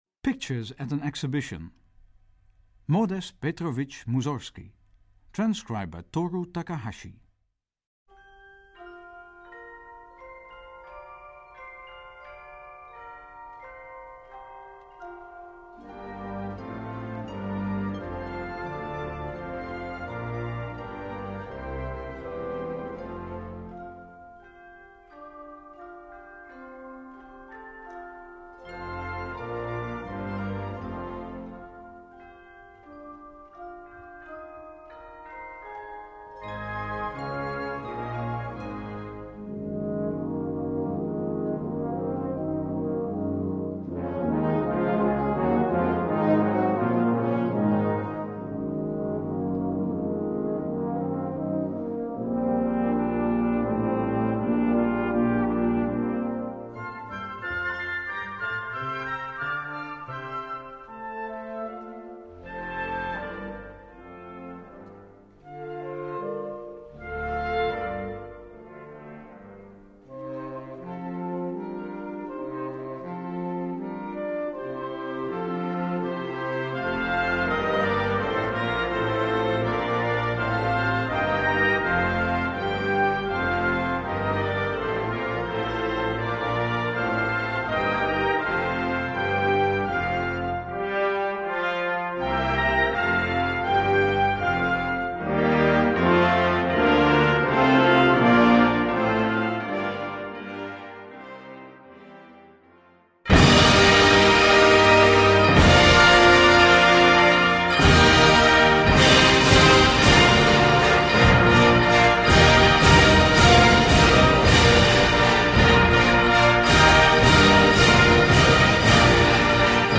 Key: Original key
Beginning and ending, 3'38"